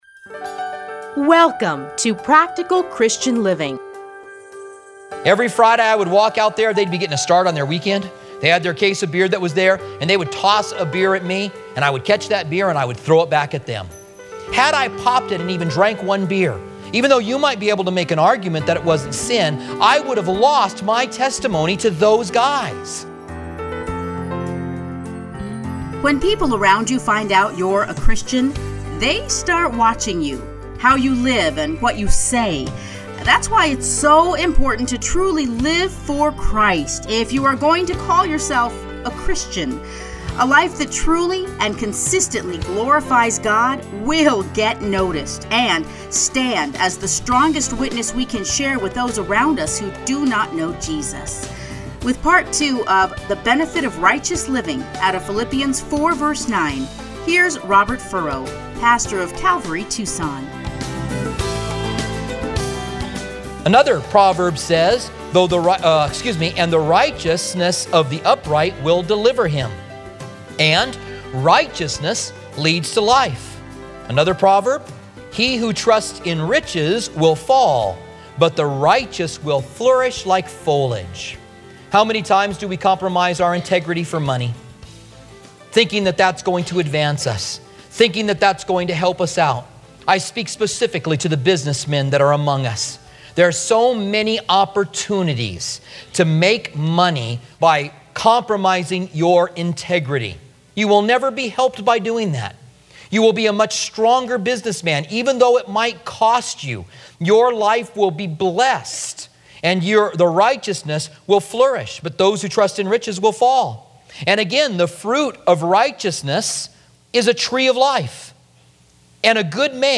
Listen here to his commentary on Philippians.